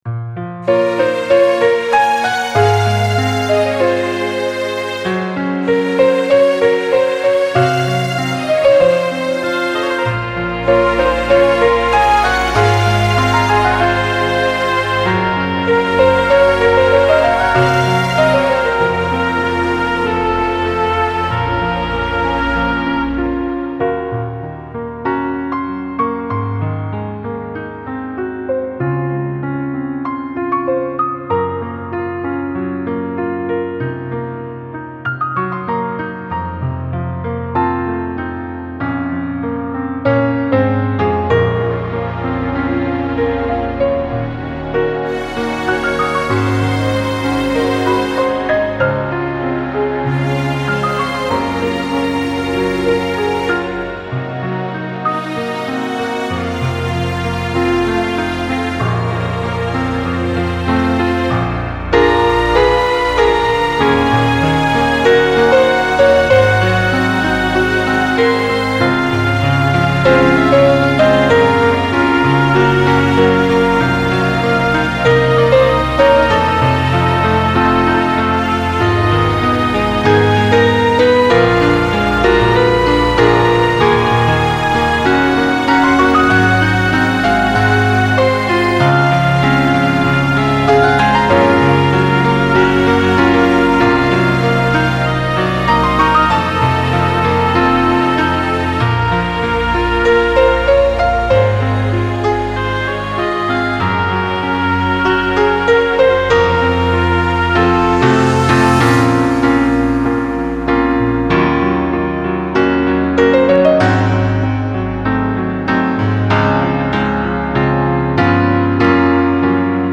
Фонограмма